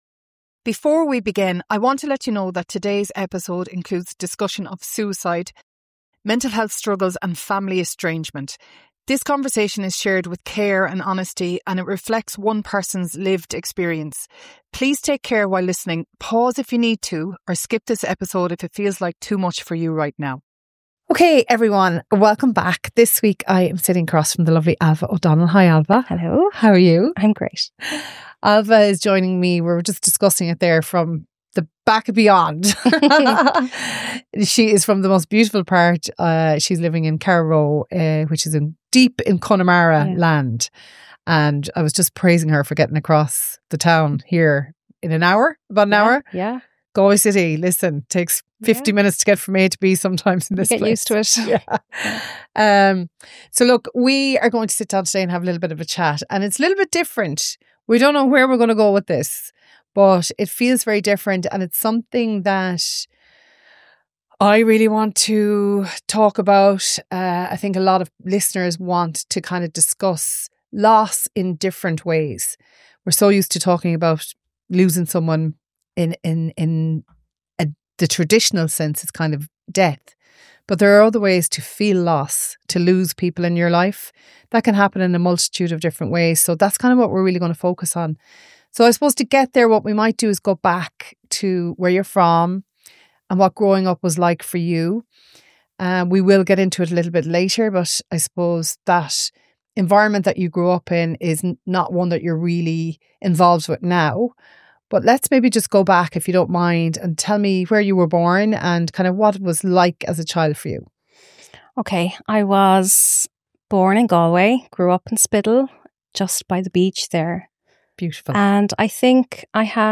At its heart, this is a conversation about survival, and learning how to become your own source of care when no one else can.